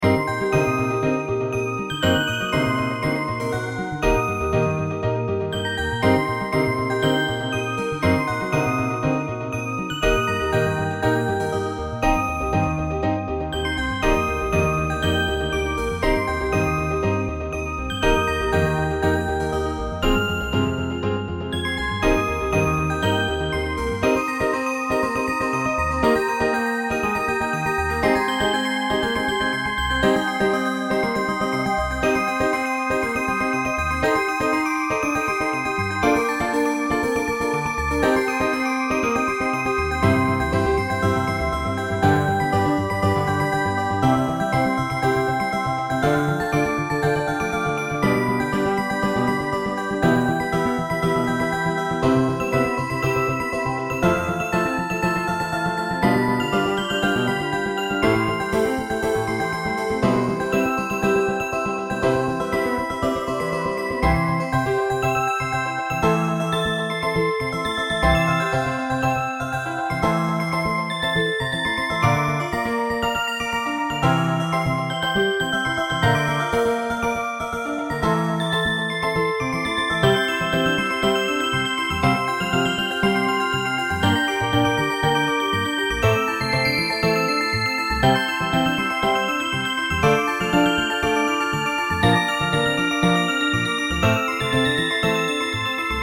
Fx5(ブライトネス)、ピアノ、アコースティックベース